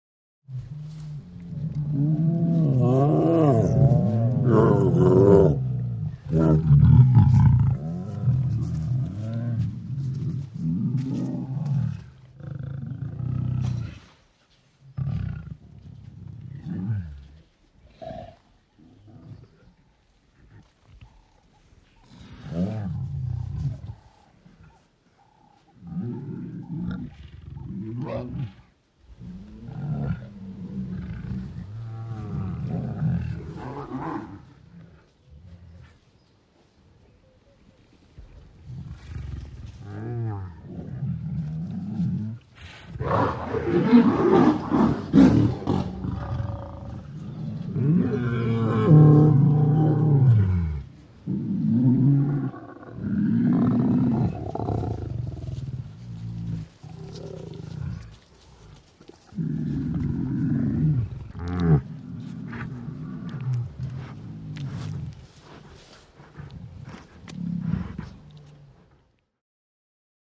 ANIMALS WILD: Restless group of lions.